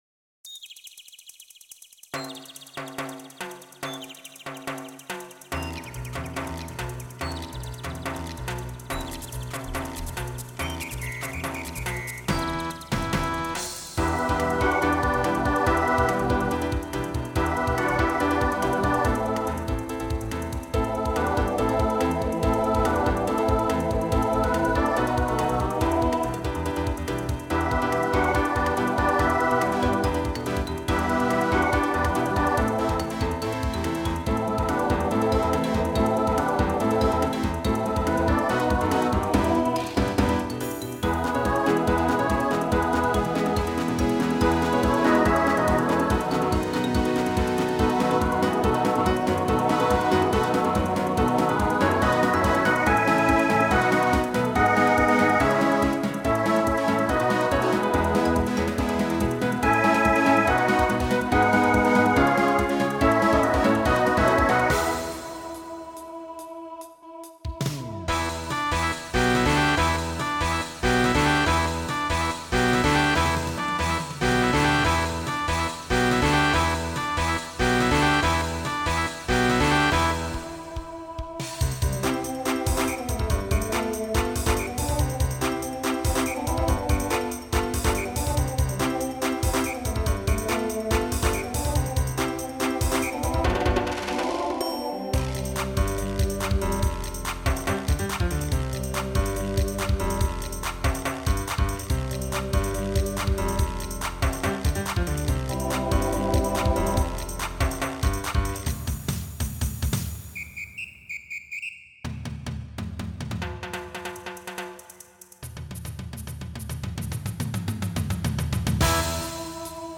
Voicing SATB Instrumental combo
Pop/Dance Decade 2000s